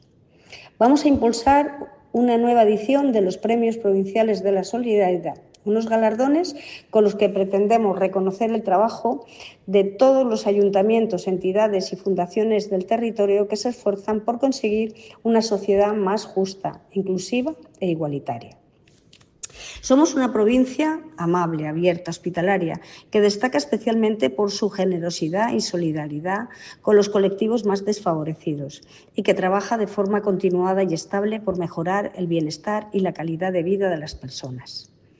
Premios-Provinciales-de-la-Solidaridad-CORTE-Loreto-Serrano.mp3